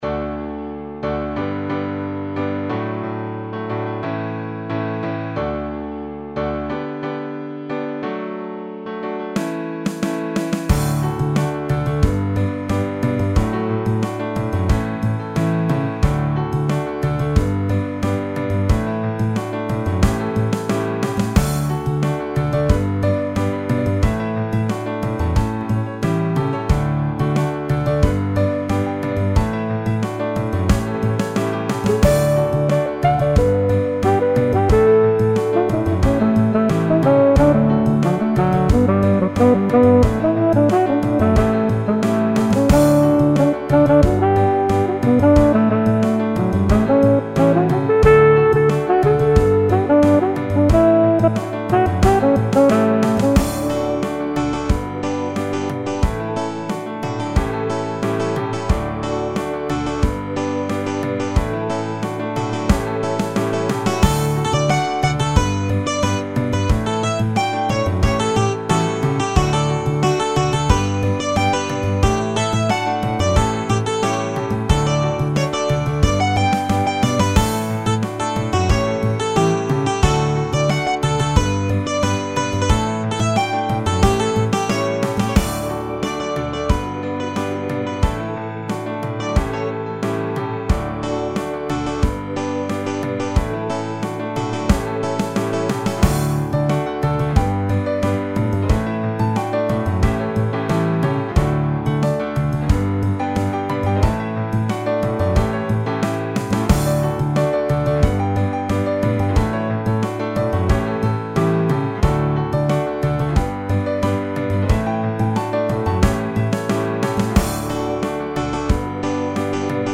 South African Capetown piano.
African Jazz
African Piano (South Africa)